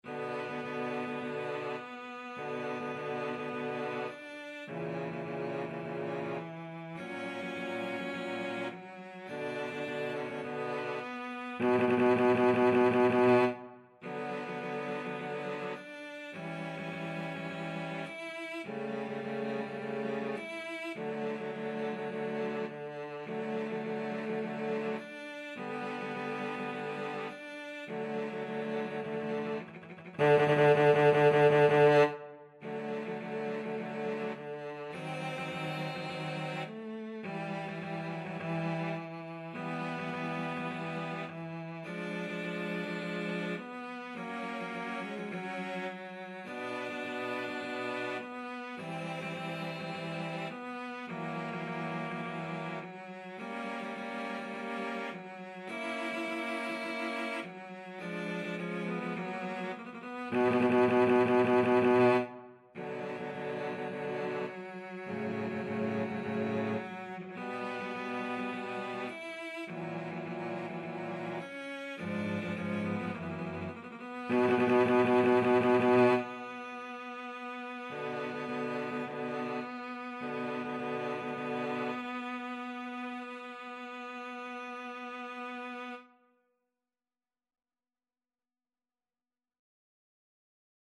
Free Sheet music for Cello Trio
B minor (Sounding Pitch) (View more B minor Music for Cello Trio )
Adagio = c. 52
4/4 (View more 4/4 Music)
Classical (View more Classical Cello Trio Music)